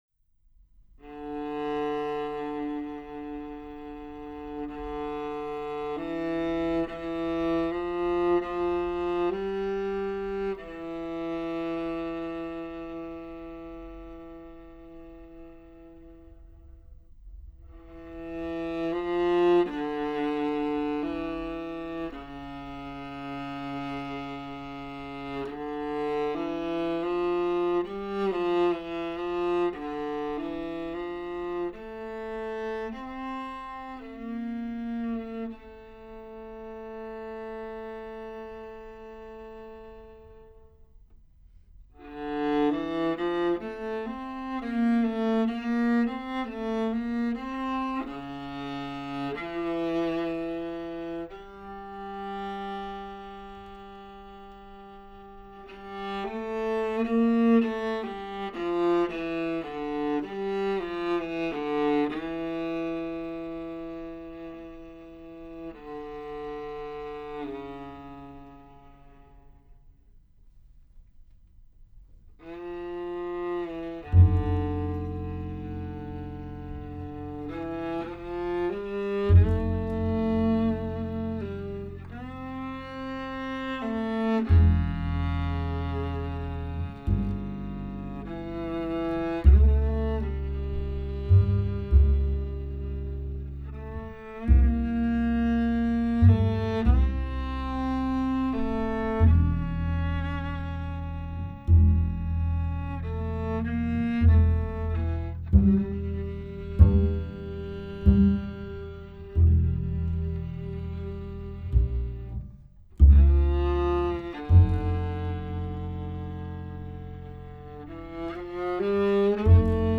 Improvisation Duo
viola
bass